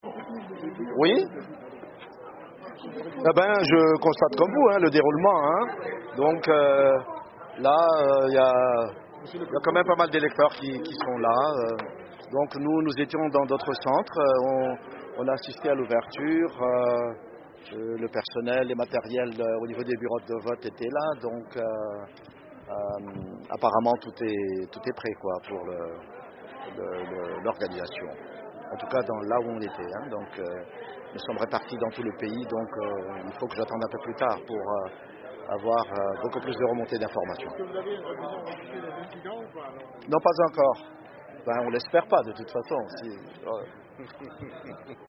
L'ancien président malgache Hery Rajaonarimampianina, chef de la délégation l'Union africaine, s'adresse aux journalistes à Lomé.